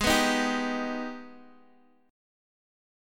G#mM7 chord